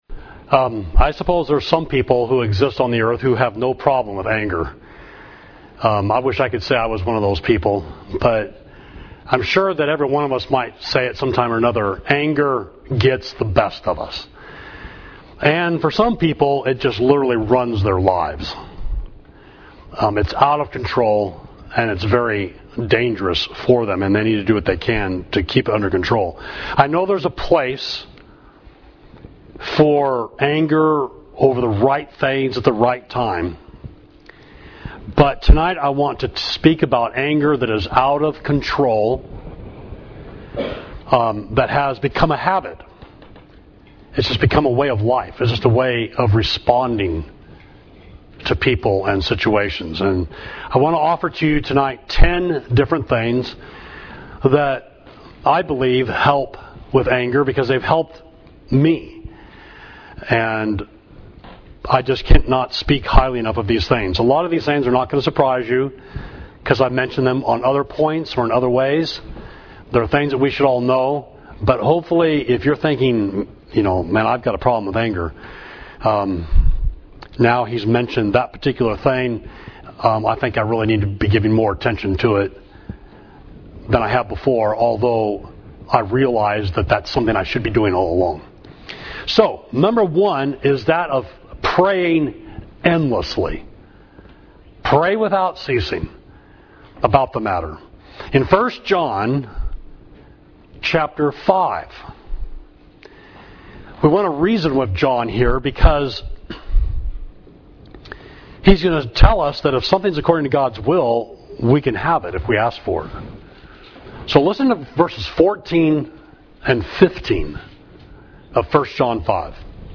Sermon: Anger